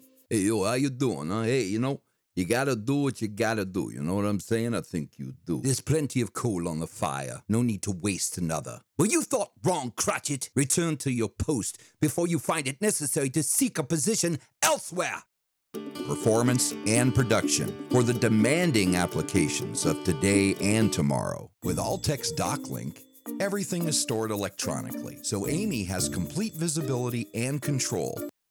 My naturally dynamic, friendly, warm and conversational voice is perfect for any commercial project or narration and telling the story of your brand.